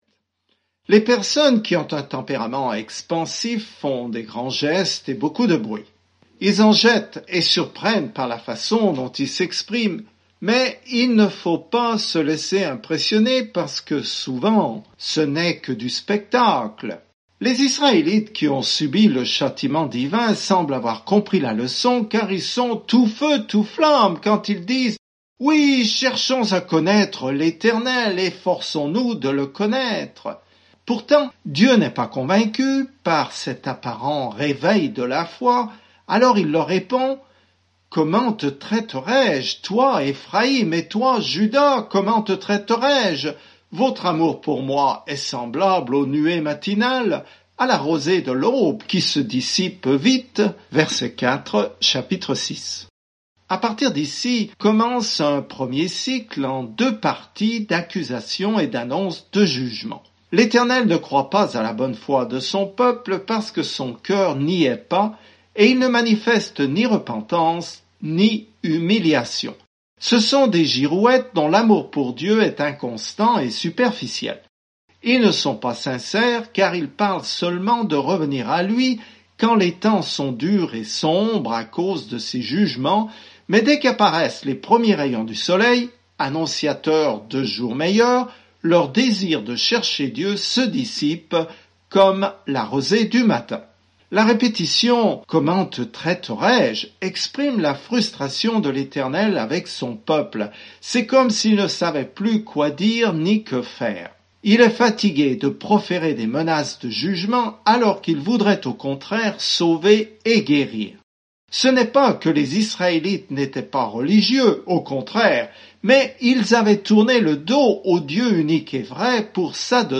Écritures Osée 6:3-11 Osée 7:1-7 Jour 8 Commencer ce plan Jour 10 À propos de ce plan Dieu a utilisé le mariage douloureux d'Osée pour illustrer ce qu'il ressent lorsque son peuple lui est infidèle, mais il s'engage à continuer de l'aimer. Parcourez quotidiennement Osée en écoutant l’étude audio et en lisant certains versets de la parole de Dieu.